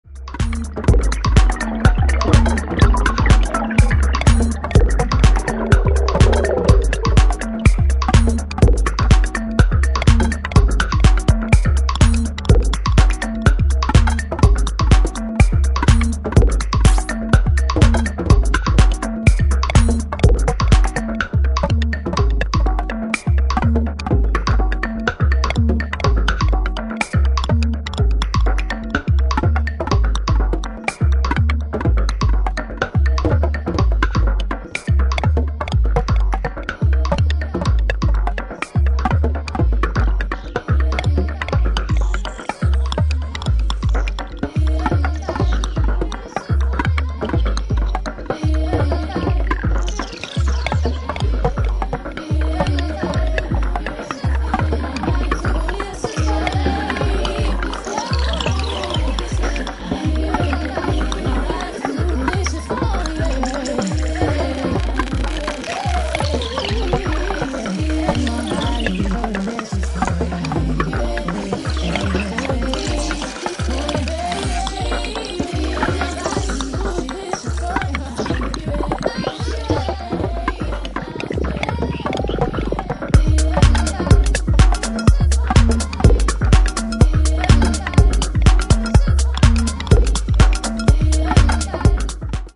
Glitched out, sub bass roller
with a warped noughties vocal for good measure.